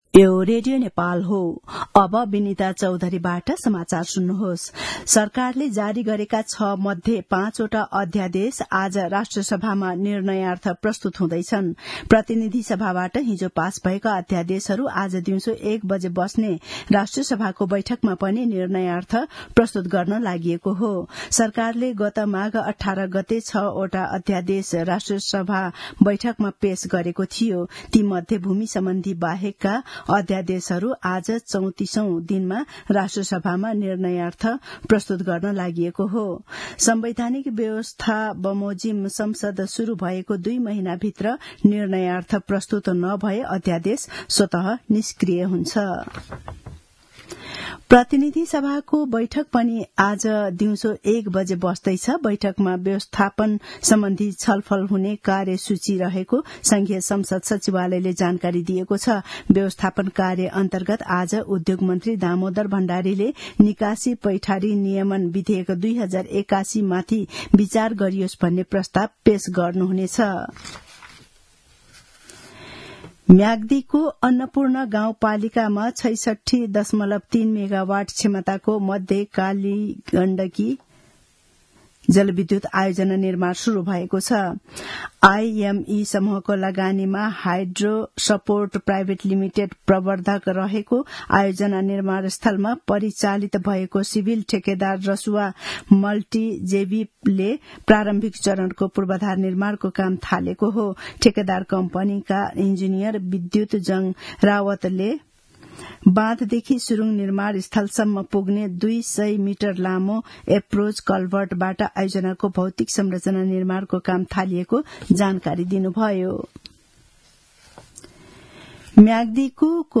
मध्यान्ह १२ बजेको नेपाली समाचार : २३ फागुन , २०८१
12-am-nepali-news-.mp3